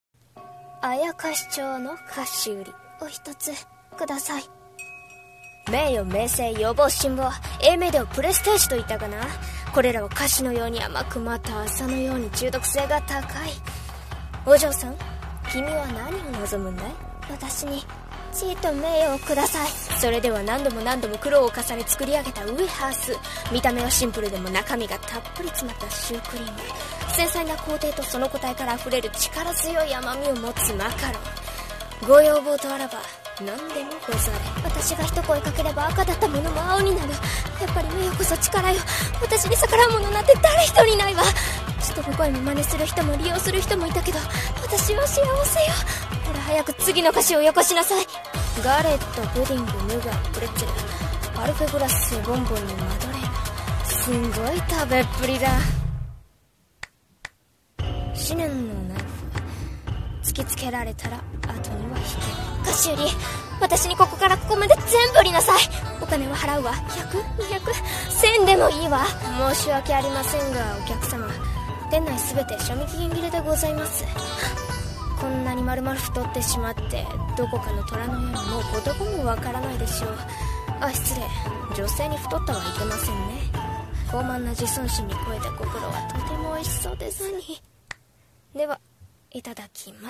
CM風声劇「妖町の菓子売」